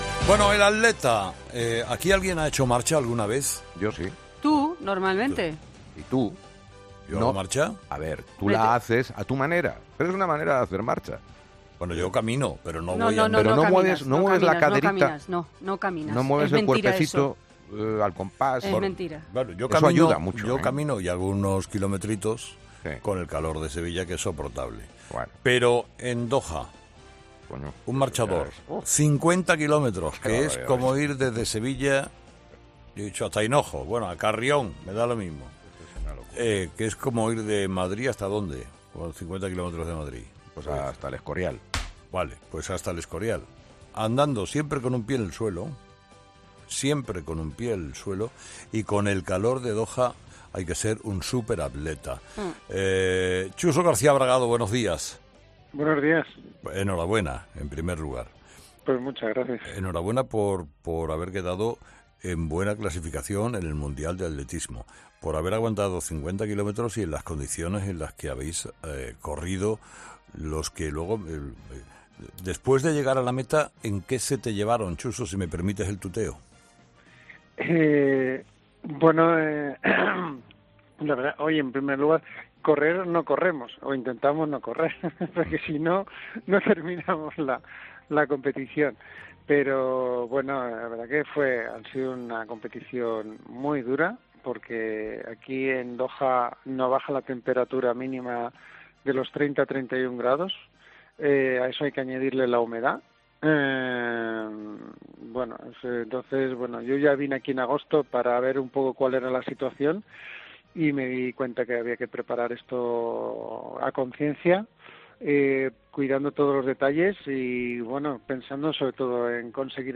El marchador estuvo en Herrera en COPE tras su octavo puesto en el Mundial de Doha que confesó que fue "muy duro", pero contento por estar en los Juegos.
El marchador estuvo este lunes con Carlos Herrera en 'Herrera en COPE' y reconoció que fue una "competición muy dura".